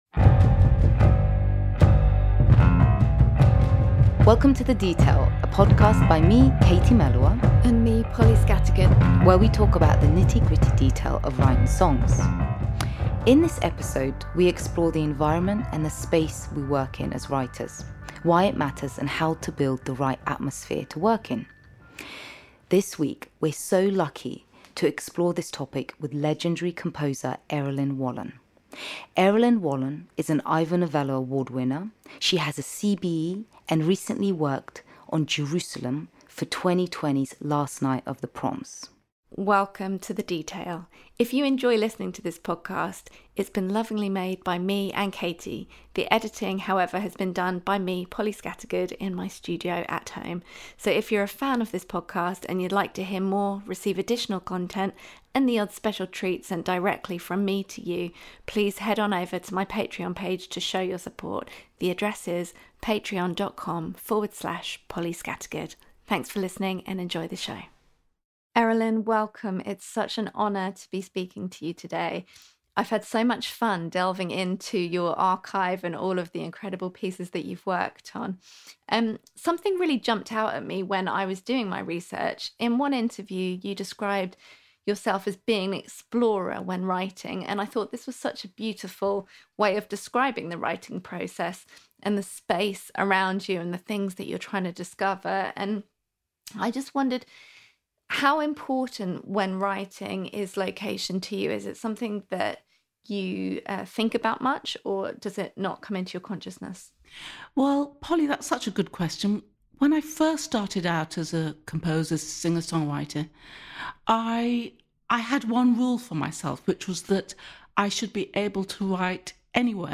A Podcast by Polly Scattergood and Katie Melua talking with special Guest Errollyn Wallen.